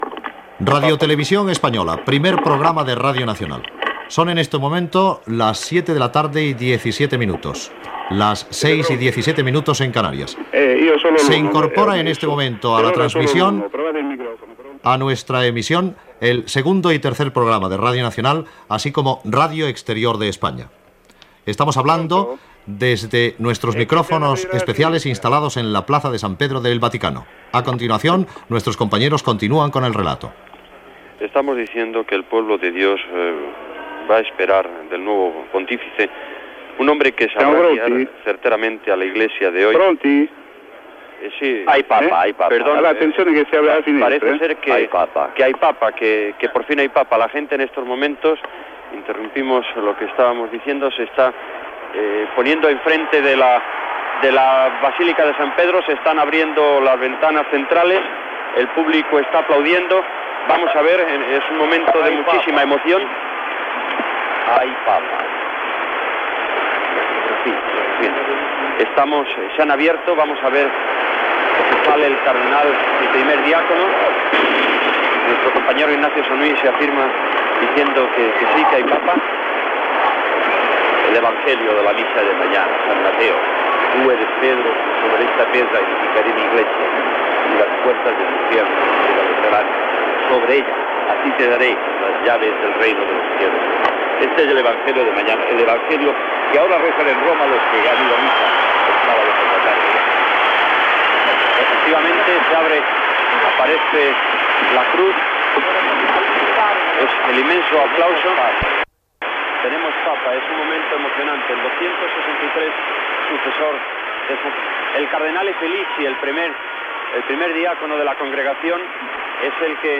Hora, identificació dels canals connectats i transmissió des de la Plaça de Sant Pere de la Ciutat del Vaticà de la proclamació del cardenal Albino Luciani com a Sant pare amb el nom de Joan Pau I
FM